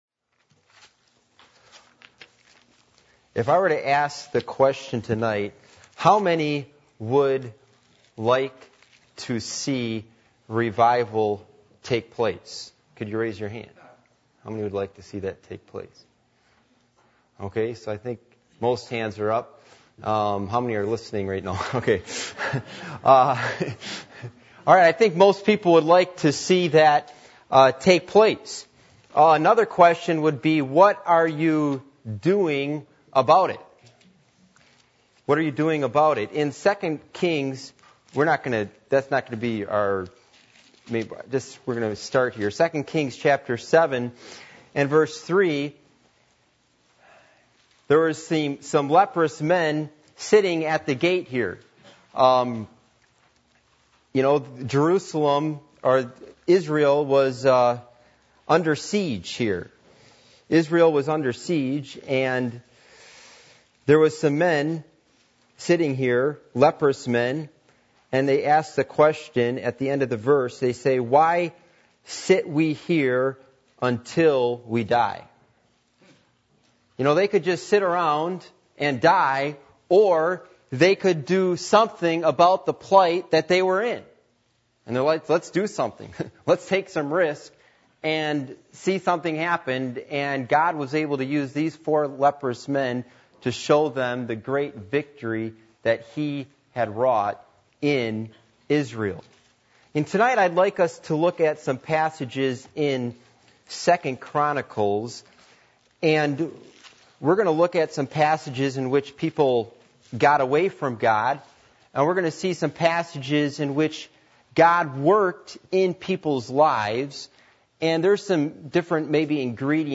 Passage: 2 Chronicles 11:1-23 Service Type: Midweek Meeting %todo_render% « The Slumber Of Apathy What Happened In The Incarnation?